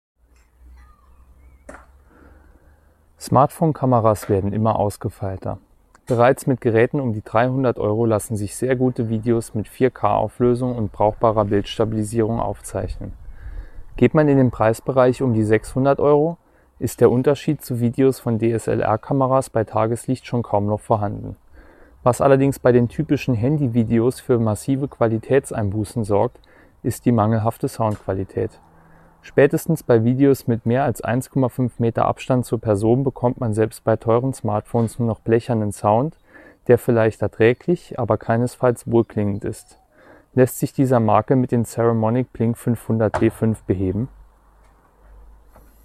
Der Ton hat erheblich mehr Tiefe und Volumen als bei normalen Aufnahmen über das Handy-Mikrofon und eignet sich durchaus für semiprofessionelle Aufnahmen.
Testaufnahme mit Lavalier draußen mit leichten Nebengeräuschen:
Außen-lav.mp3